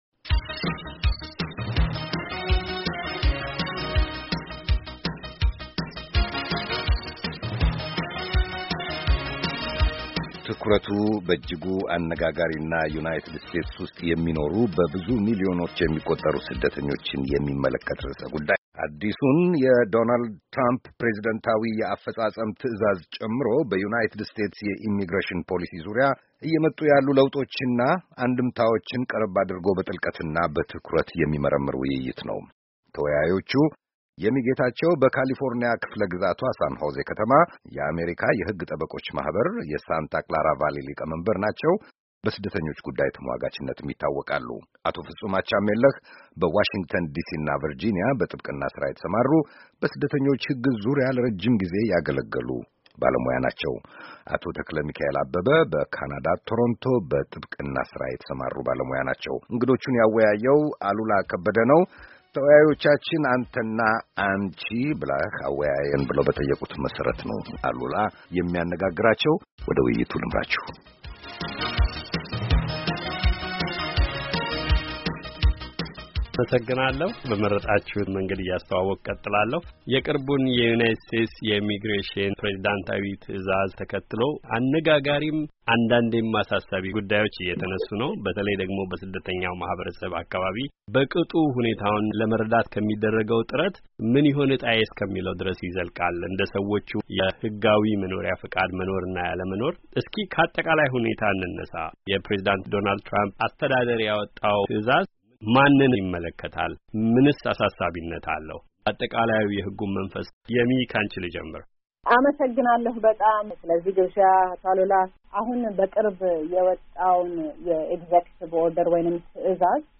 የዩናይትድ ስቴትስ የኢሚግሬሽን-ነክ ጉዳዮችና አዲሱ ፕሬዝዳንታዊ ማዘዣ ተከታታይ ውይይት